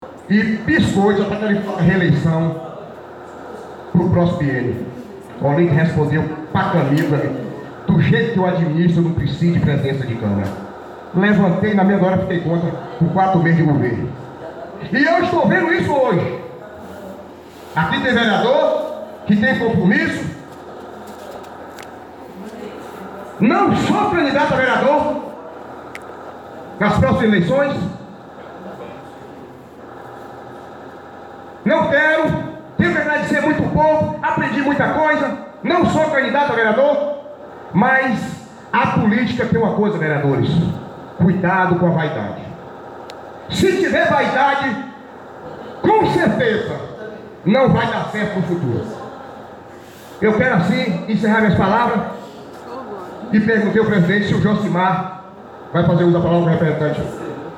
O vereador Carlinhos de Daniel do (PP), Partido Progressista, usou a tribuna da Câmara Municipal de vereadores, nesta quinta-feira (12), para afirmar que não será candidato a vereador nas próximas eleições.
Em sua fala o vereador falou sobre algumas cenas já vividas e que está presenciando a mesma história nos dias de hoje. O Blog do Edyy esteve presente na sessão e gravou o momento em que o edil fala sobre sua desistência em 2020.